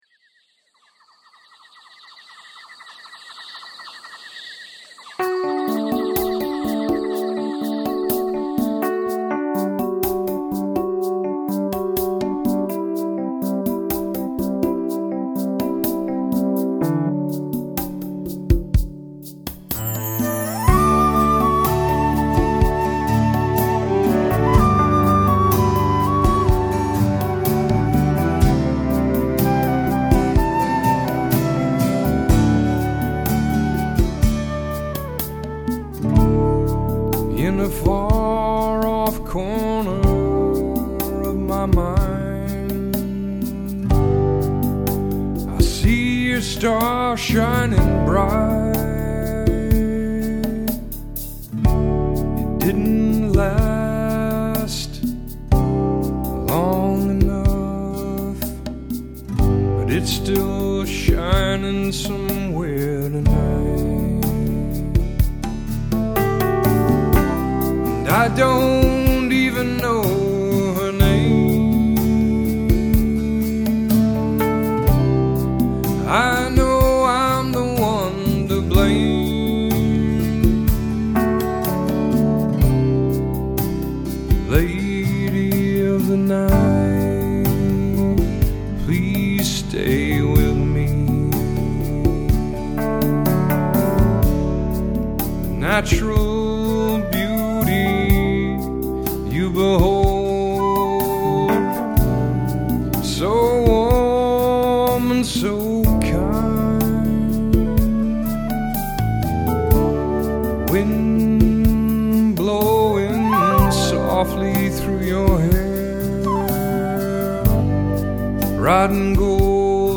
Guitars
Keyboards
Drums